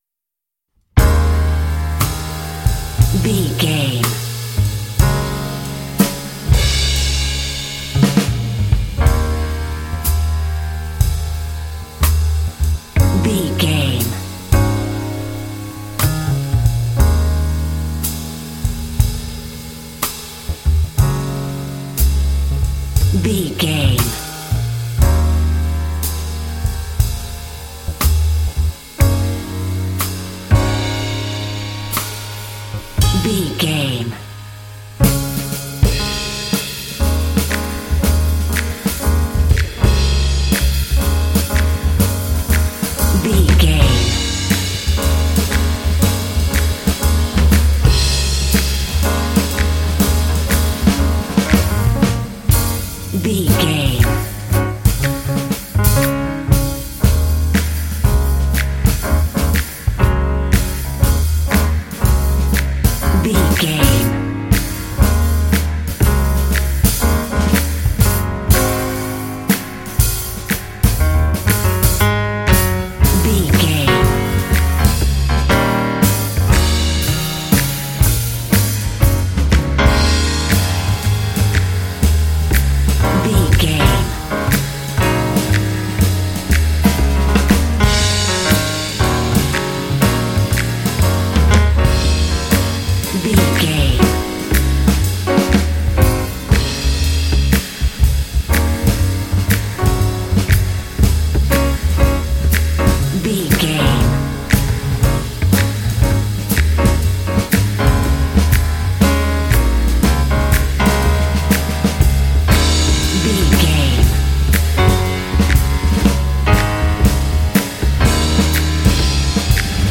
Aeolian/Minor
E♭
smooth
calm
double bass
piano
drums
smooth jazz